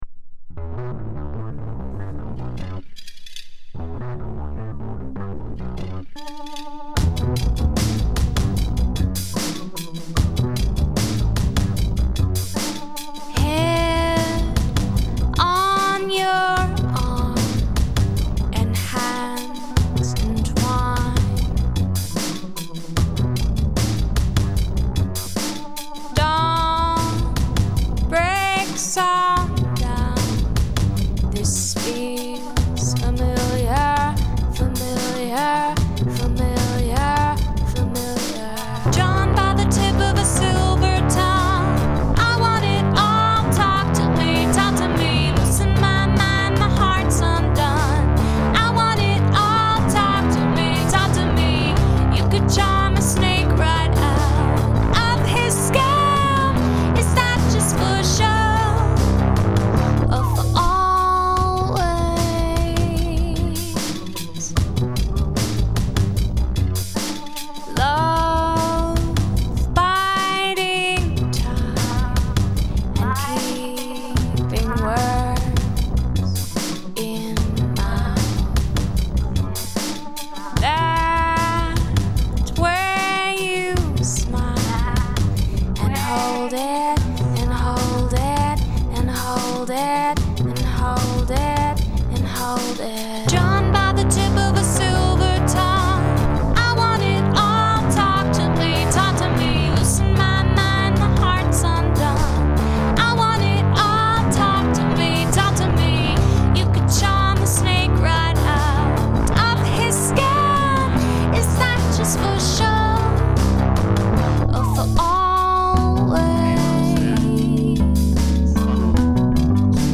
Guest Rap